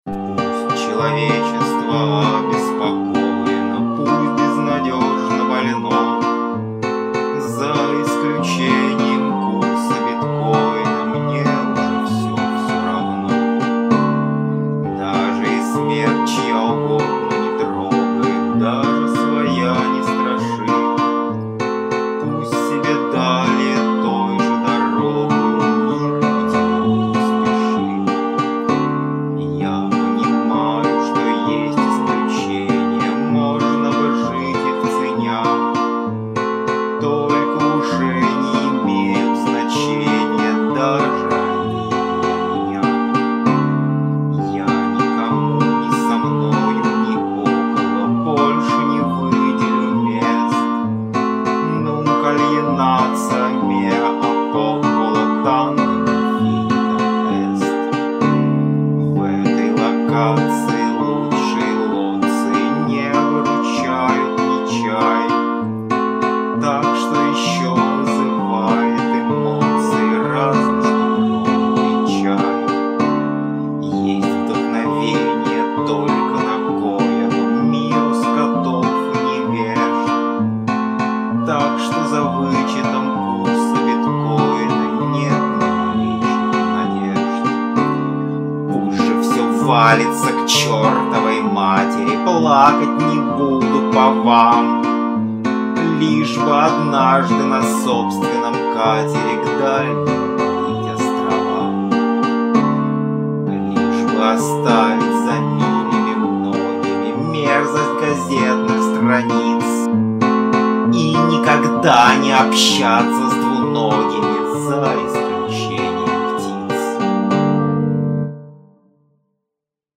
alienatio_gtr_voice.mp3